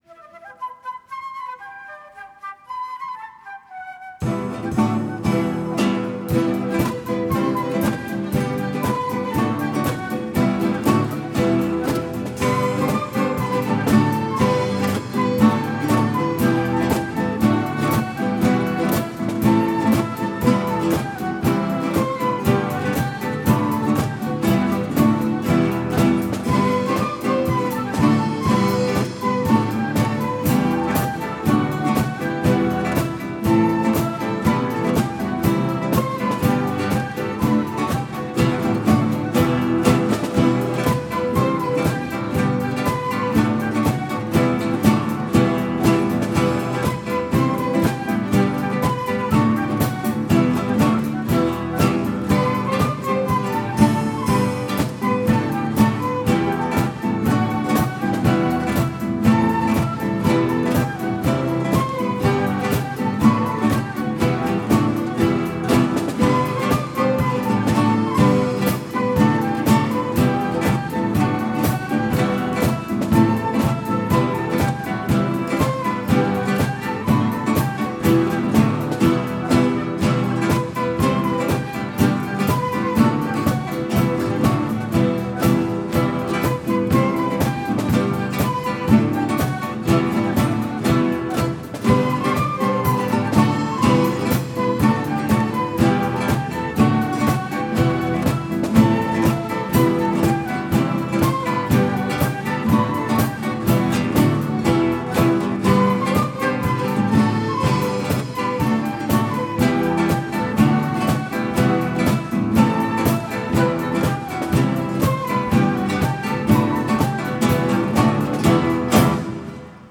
Site web de la classe de guitare classique du Conservatoire d'Aix-les-Bains (Savoie)